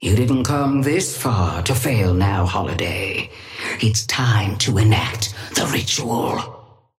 Sapphire Flame voice line - You didn't come this far to fail now, Holiday.
Patron_female_ally_astro_start_03.mp3